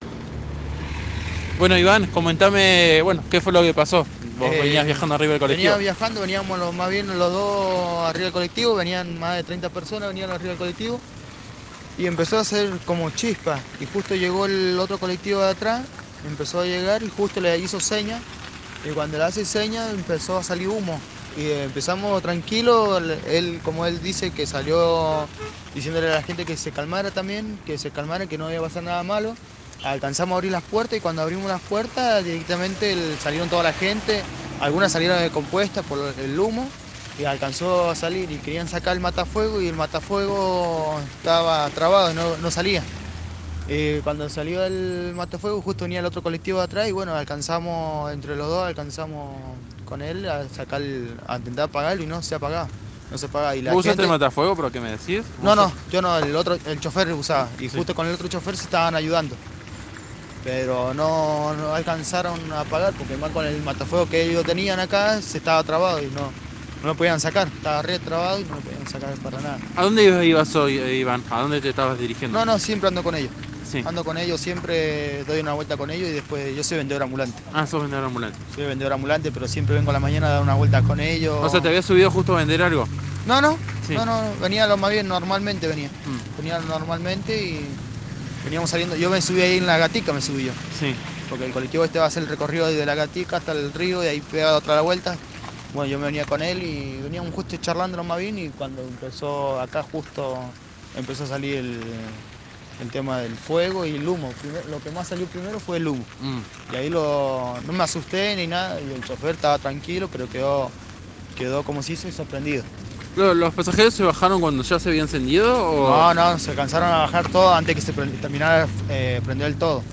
el pasajero